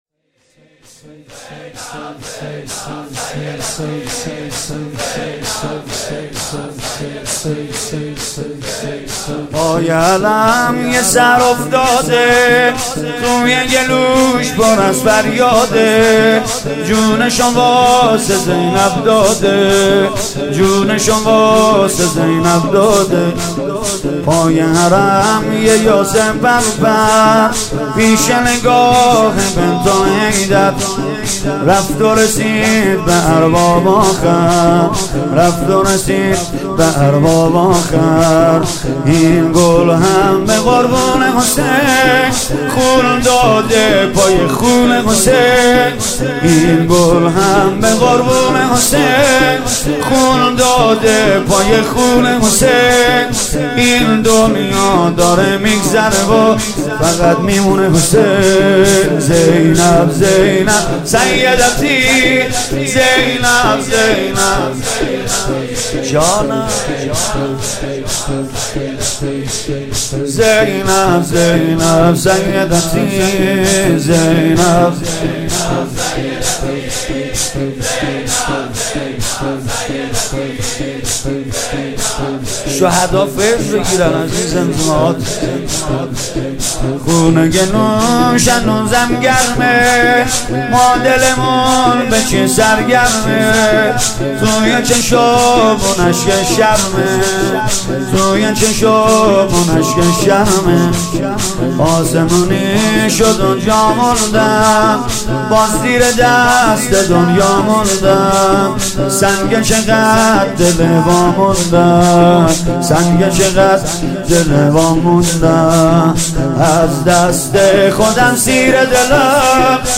شور جدید